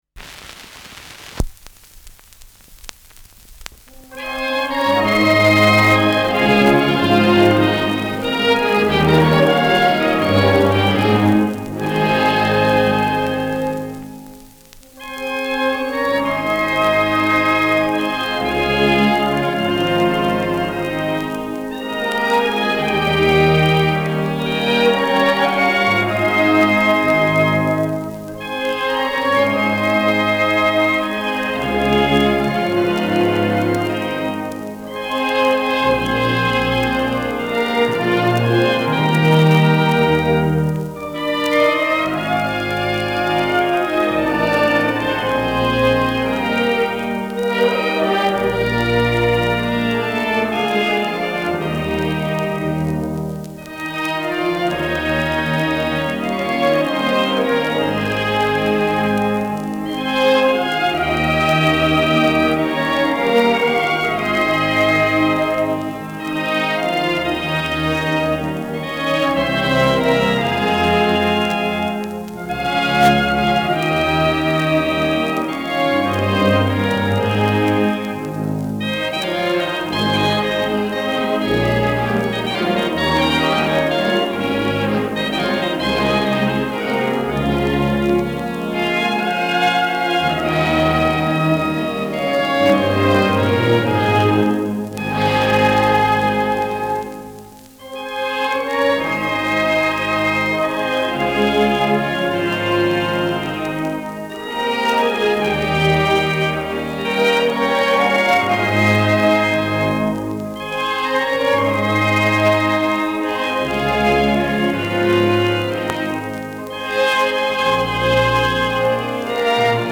Schellackplatte
Abgespielt : Leiern : Durchgehend leichtes Knacken : Erhöhter Klirrfaktor
Große Besetzung mit viel Hall, die einen „symphonischen Klang“ erzeugt.